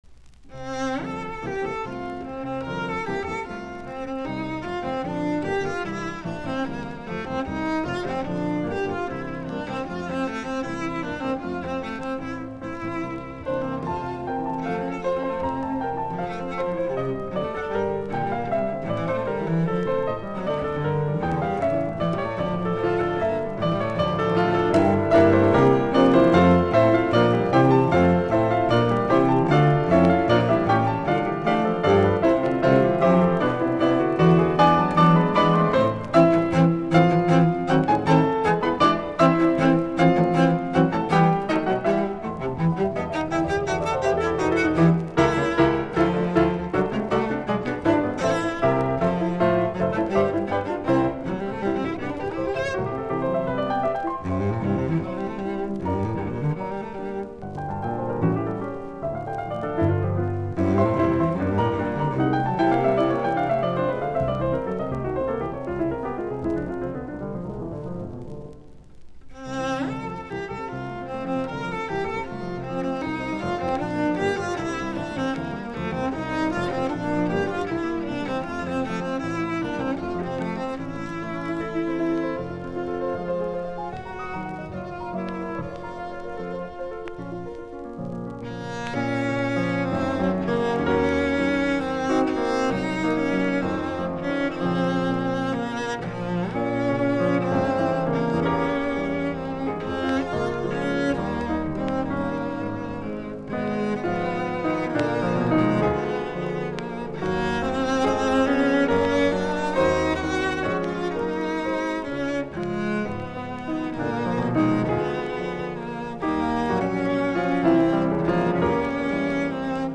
Sonaatit, sello, piano, nro 2, op99, F-duuri
musiikkiäänite
Soitinnus: Sello, piano.